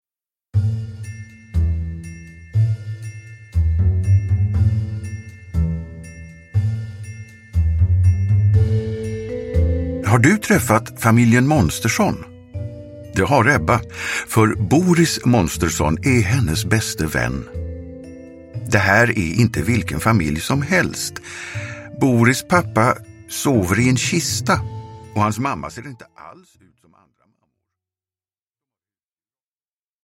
Pappa Monstersson fyller år – Ljudbok – Laddas ner